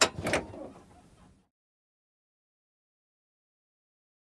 handbrake_down.ogg